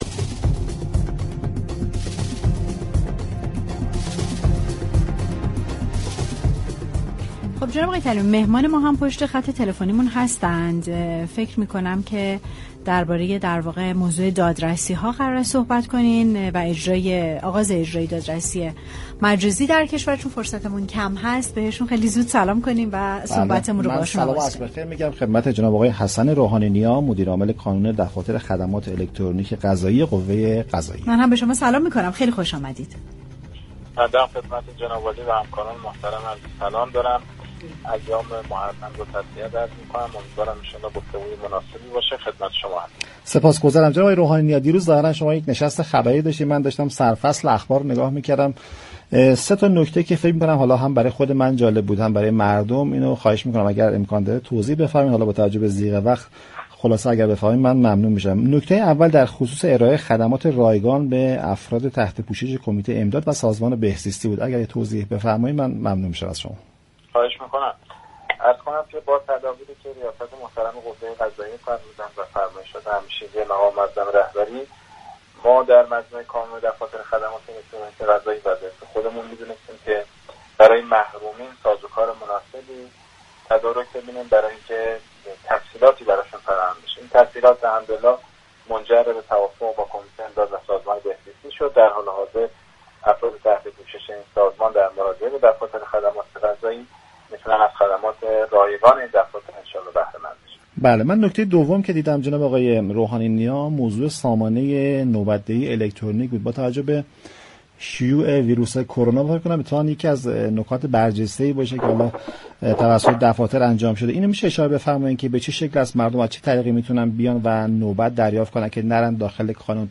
در گفتگو با برنامه تهران من رادیو تهران